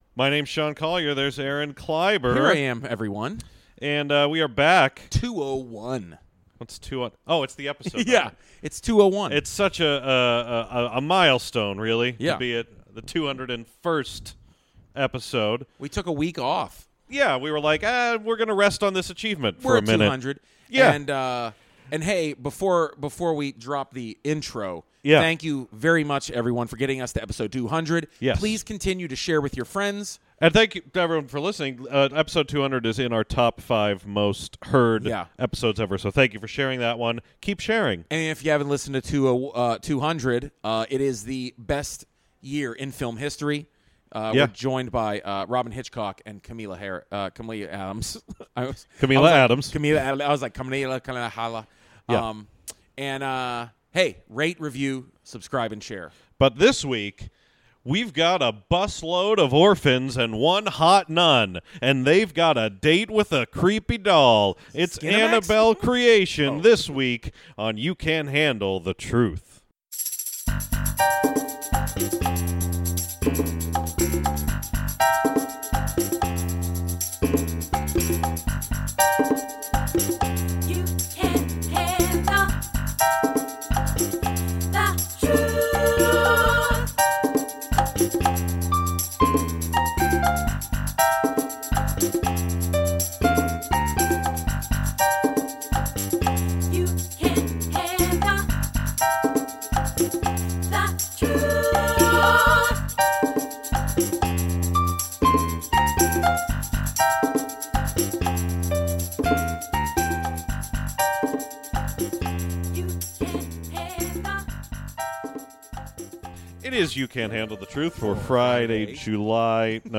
Every week we bring you reviews of movies, recorded immediately after an advance screening.